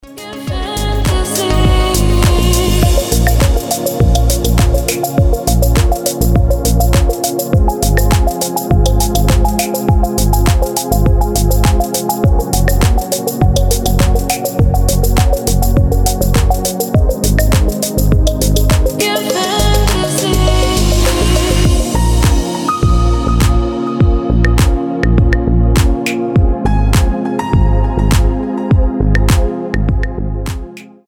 • Качество: 320, Stereo
deep house
Vocal House